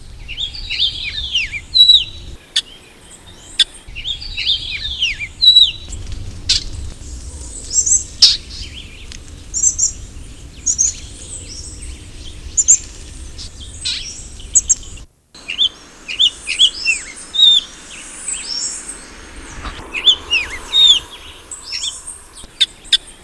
"Puerto Rican Oriole"
Icterus portoricensis
calandria.wav